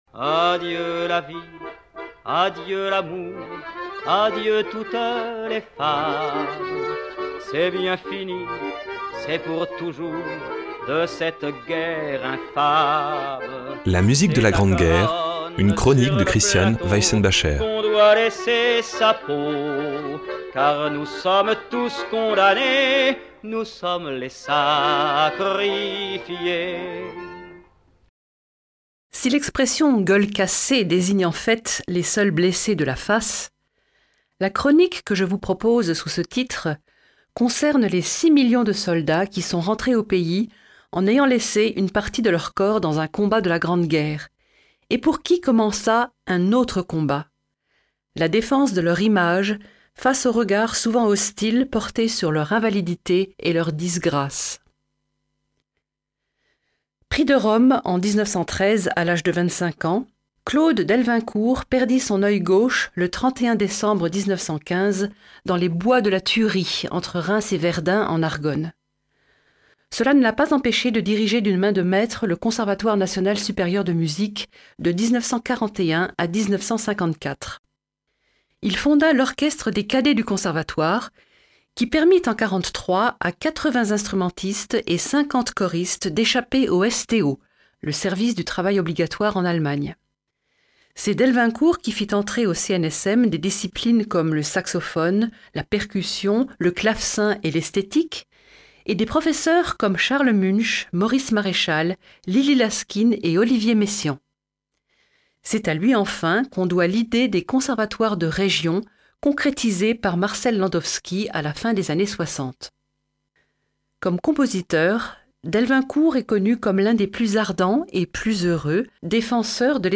1. Delvincourt,  Danceries pour violon et piano, « Farandole »
2. Hadley, Fen and Flood (cantate), « The Painful Plough » (IIe partie)
3. Prokofiev, 4ème concerto pour piano, 4ème mouvement (Vivace)